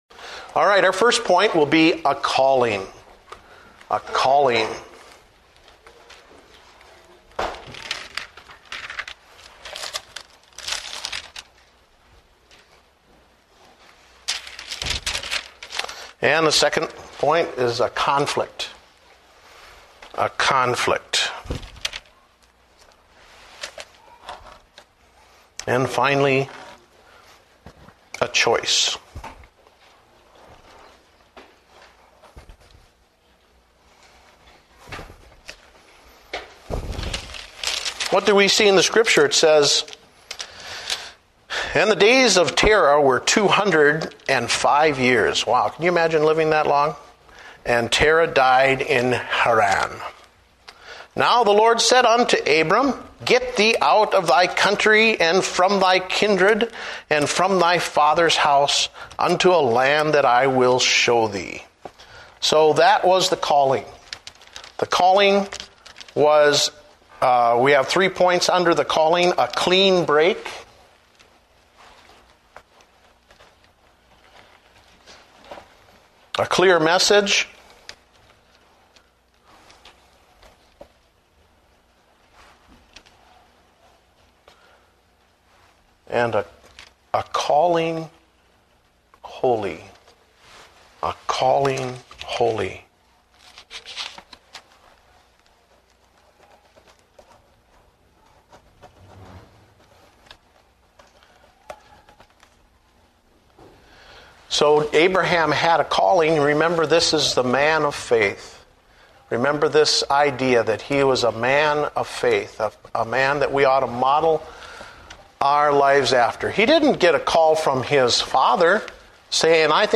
Date: February 8, 2009 (Adult Sunday School)